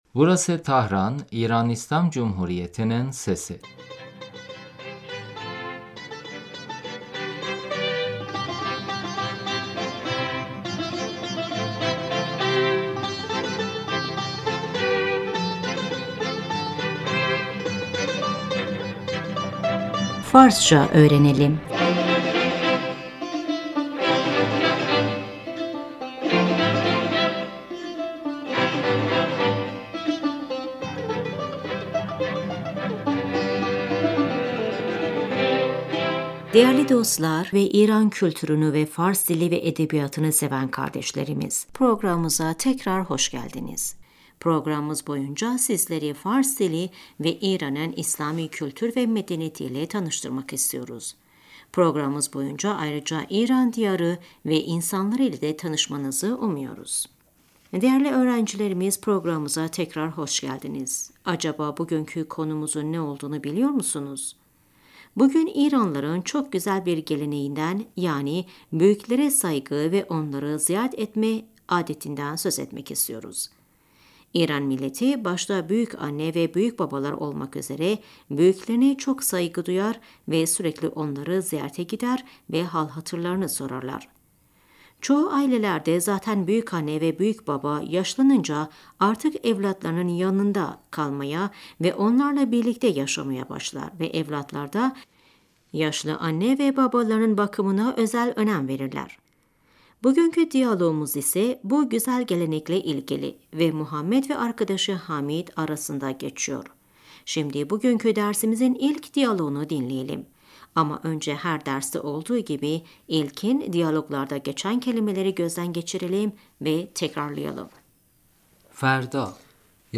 Şimdi bugünkü dersimizin ilk diyaloğunu dinleyelim.